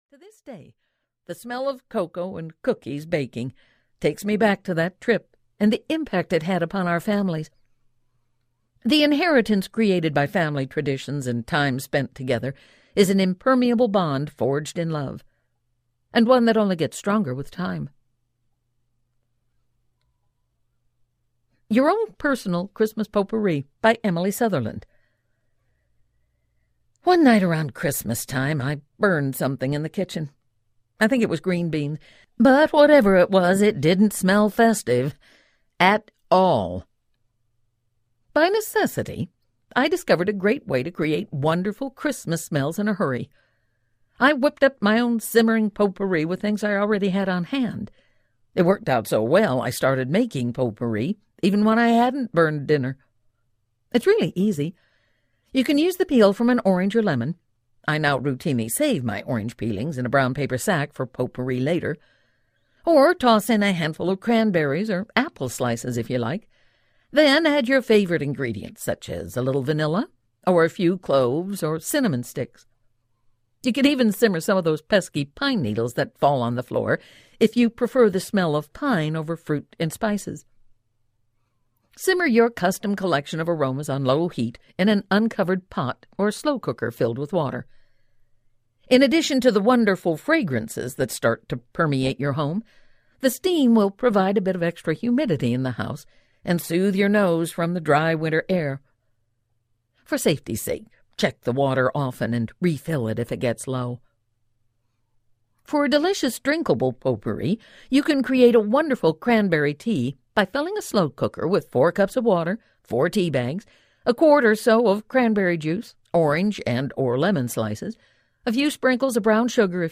A Homecoming Christmas Audiobook
4.65 Hrs. – Unabridged